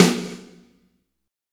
Index of /90_sSampleCDs/Roland L-CDX-01/KIT_Drum Kits 1/KIT_R&R Kit 3
SNR PLATE 05.wav